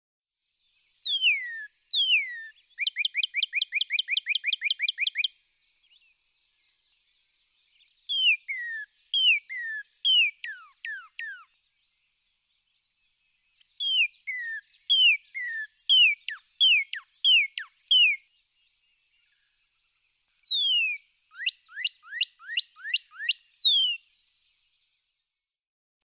Northern cardinal
One example of each of those four song types (A B C D).
122_Northern_Cardinal.mp3